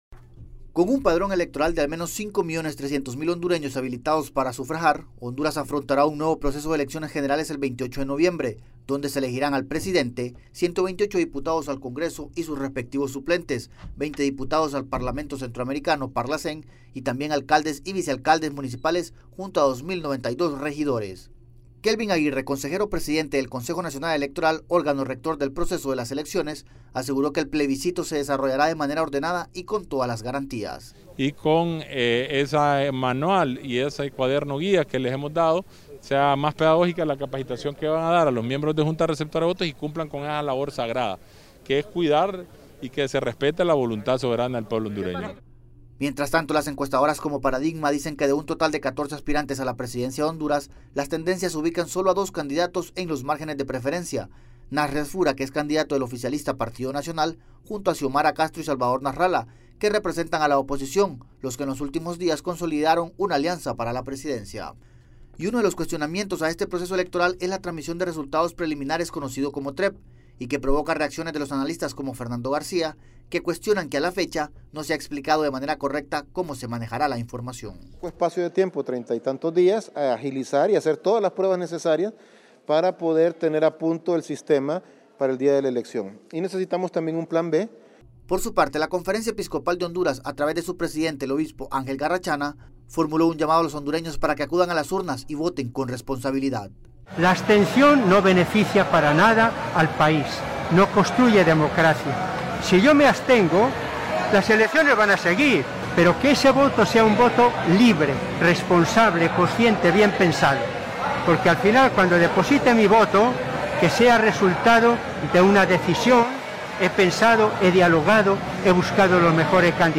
Honduras está a menos de un mes para desarrollar un proceso de elecciones generales para elegir a sus nuevas autoridades y exigir que asuman el compromiso de hacer frente a los desafíos más urgentes. Desde Tegucigalpa informa el corresponsal de la Voz de América